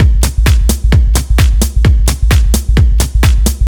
• tech kick sample wooble bass.wav
tech_kick_sample_wooble_bass_u4q.wav